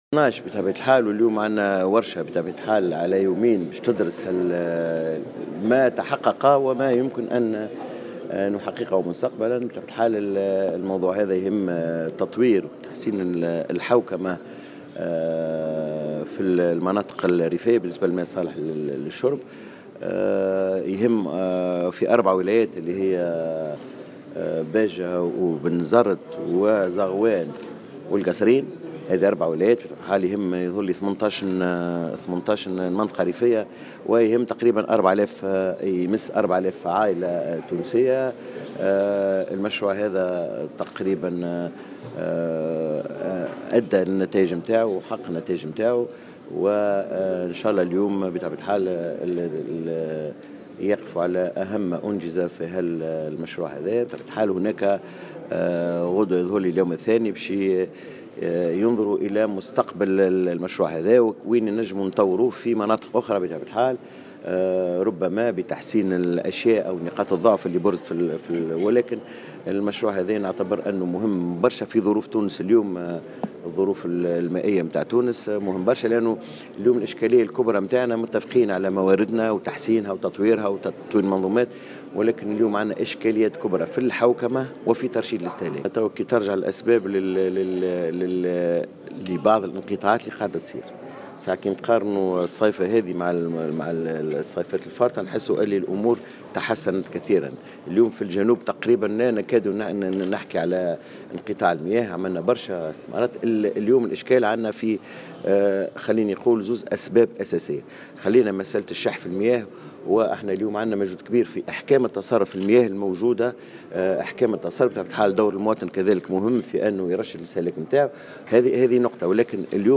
وجاءت تصريحاته على هامش ندوة واكبتها "الجوهرة أف أم" حول تحسين الحوكمة المحلية للماء الصالح للشرب في ولايات زغوان والقصرين وباجة وبنزرت.